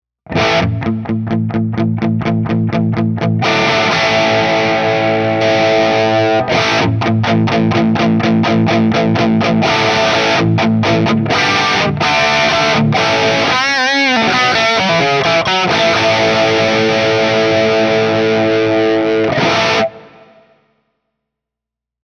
Tutte le clip audio sono state registrate con amplificatori reali iniziando con Ignition spento nei primi secondi per poi accenderlo fino alla fine della clip.
Chitarra: Fender Stratocaster (pickup al ponte)
Testata: Marshall Plexi 1959 sul canale High Treble a metà volume
Cassa: Marshall 1960 con coni Celestion G12T75